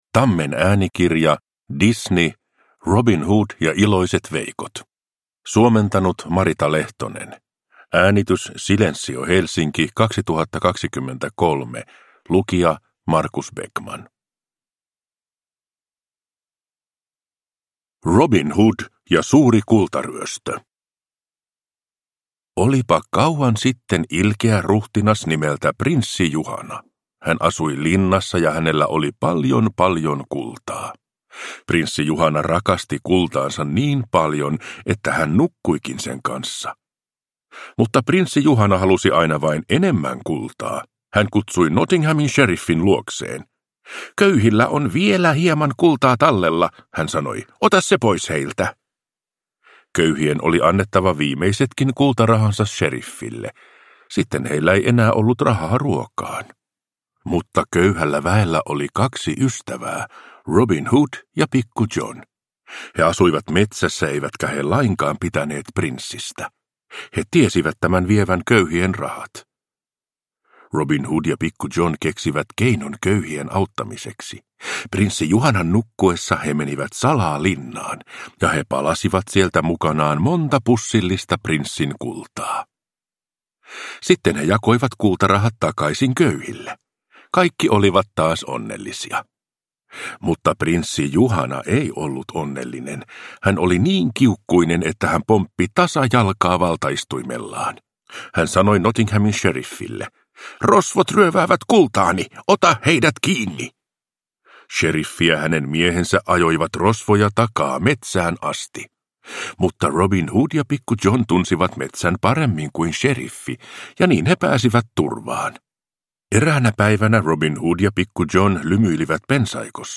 Robin Hood ja iloiset veikot – Ljudbok